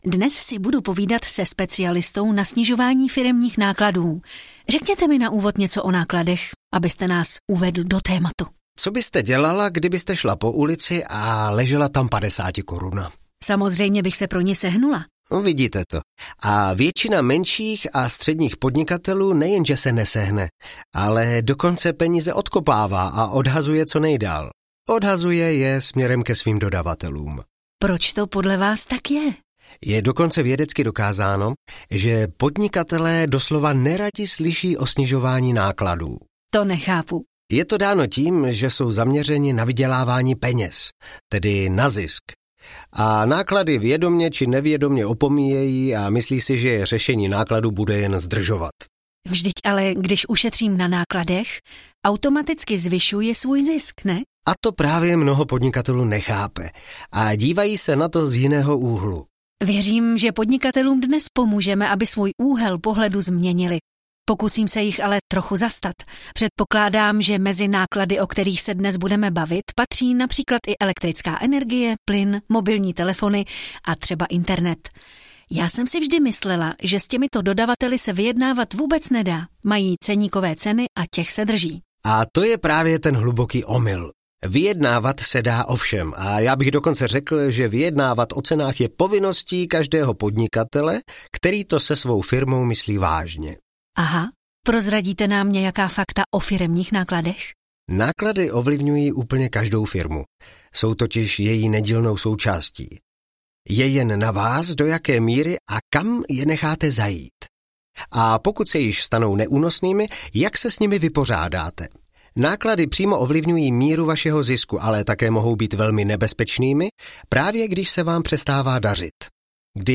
Jak zvyšovat firemní zisk snižováním nákladů audiokniha
Ukázka z knihy